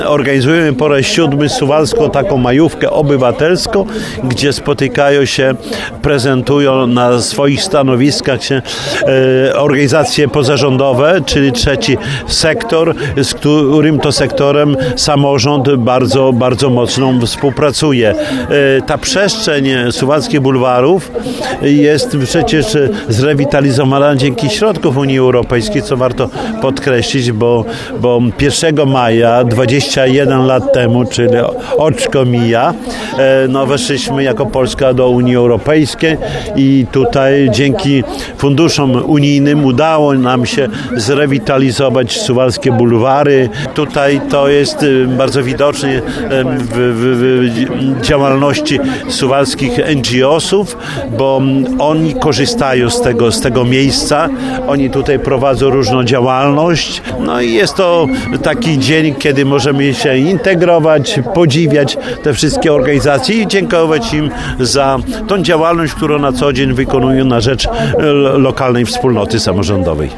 W otwarciu Majówki uczestniczył Czesław Renkiewicz, prezydent Suwałk.